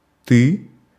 Ääntäminen
Synonyymit tacite Ääntäminen France: IPA: /ˈty/ Quebec: IPA: /tsy/ Haettu sana löytyi näillä lähdekielillä: ranska Käännös Ääninäyte 1. ты (ty) Suku: m .